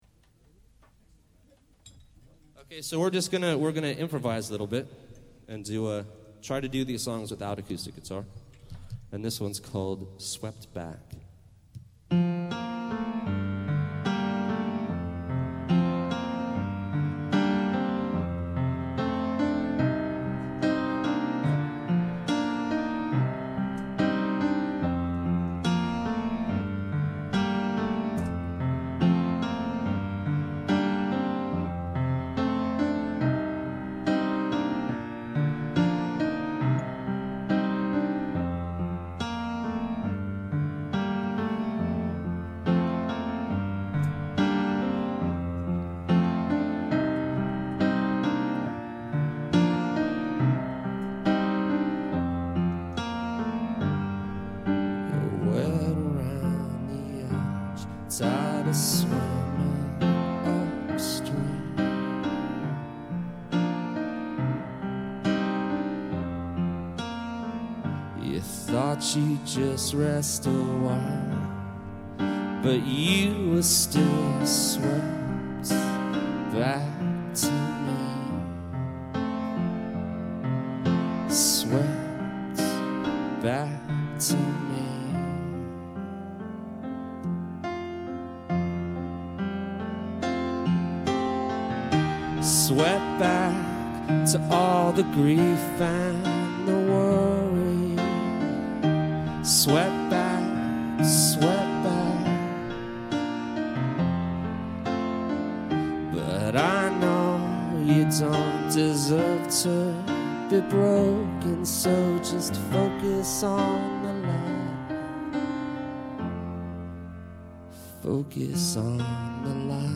live aus dem Club?? Piano-only)